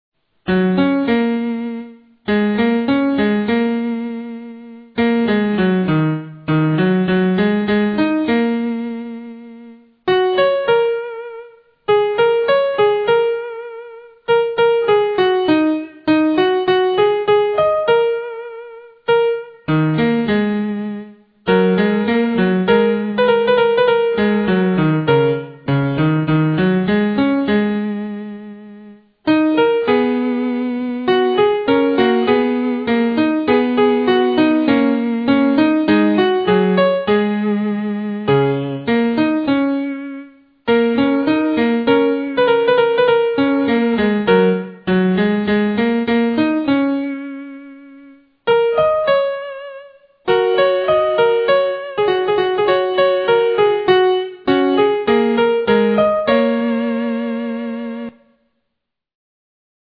Cette courte pièce, composée en 2025, utilise une gamme pentatonique. Après l'avoir initialement composée pour deux flûtes (flûte et flûte alto), en ut majeur, Jean-Pierre Vial en a publié une transposition plus idiomatique, pour les touches noires du piano, en fa dièse majeur. La gamme pentatonique évoque les musiques asiatiques, et en particulier celles du Pays du Soleil Levant.